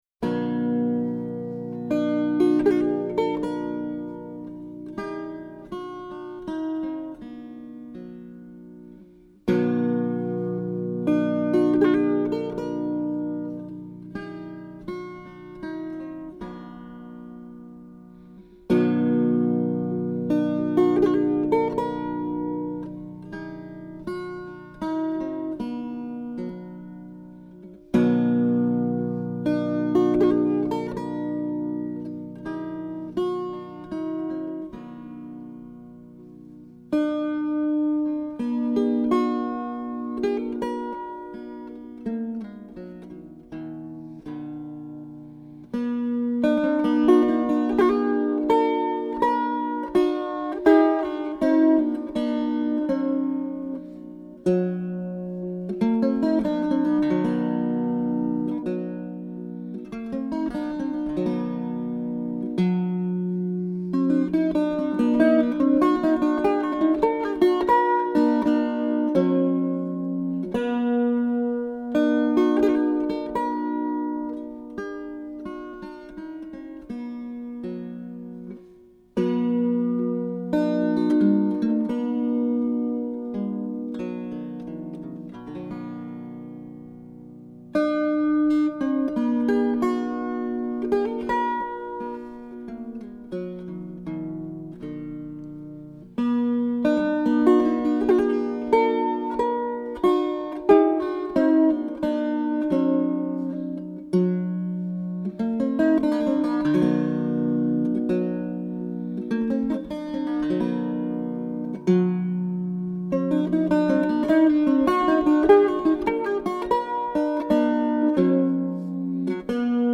18th-century wire-strung guittar